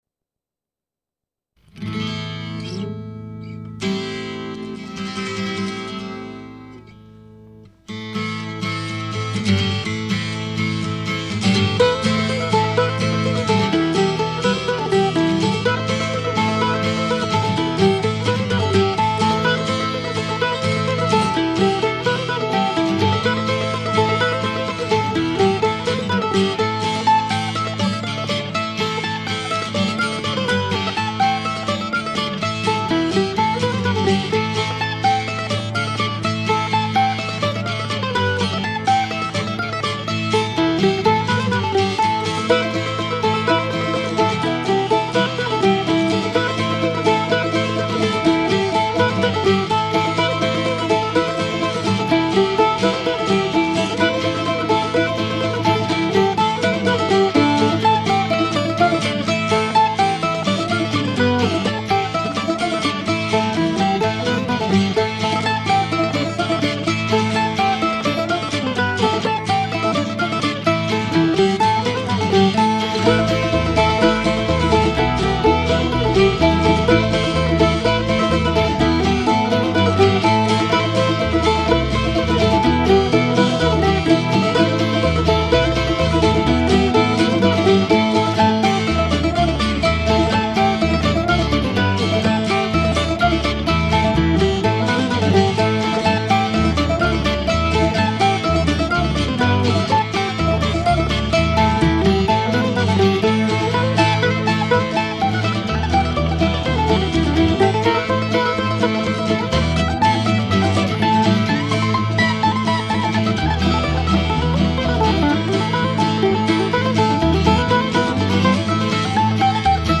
This comes from the same cassette tape that I produced in my apartment with my old Fostex 4-track machine and that I talked about at some length back in a post from Oct. 12, 2013.
Today's recording is notable to me because it begins with a flourish from a long-forgotten 12-string guitar that I used to own.
This, I think, is the guitar on this tape.
Then I play improvised choruses on each instrument, followed by a closing statement of the melody. (I was thinking in jazz even though the tune is a simple reel.) Somewhere in there I also added an electric bass track.
I settled for what came out the first or second time, so they have a certain rough quality. Charming if you are being kind, sloppy if you take the other approach.